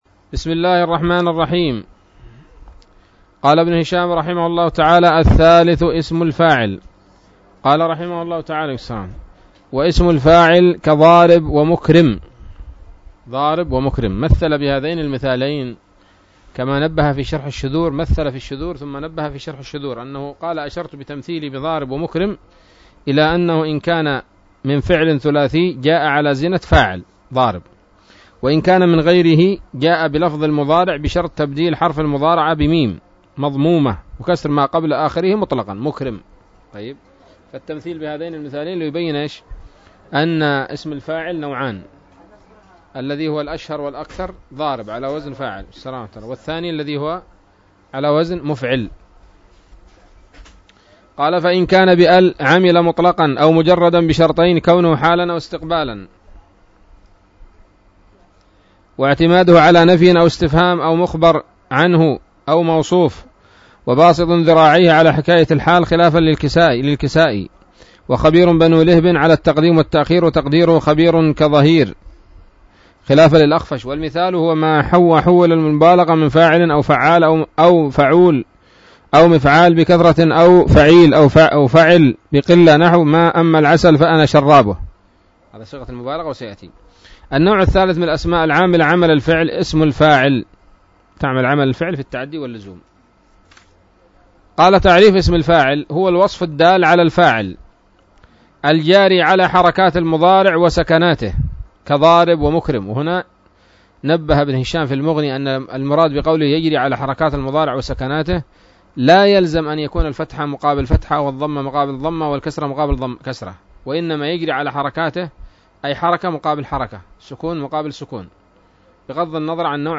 الدرس الثامن بعد المائة من شرح قطر الندى وبل الصدى